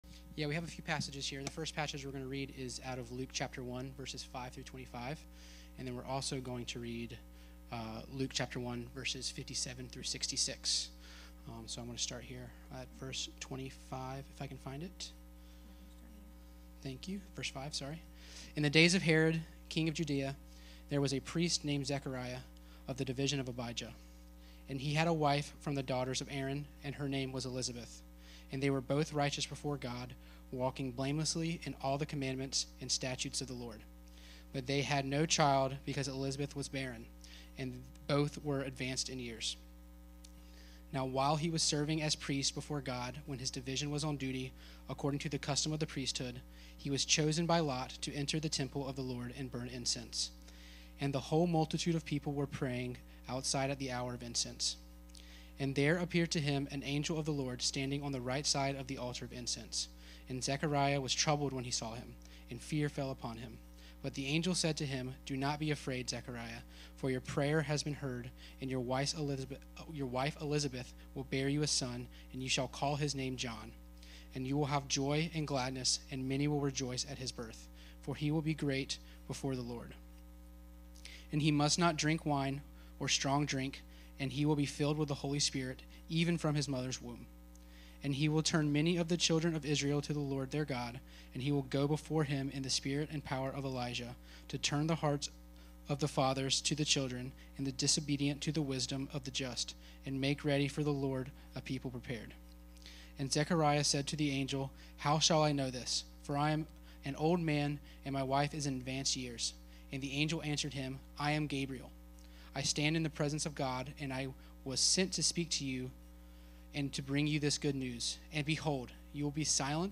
Series: The Tests of Christmas Service Type: Sunday 10am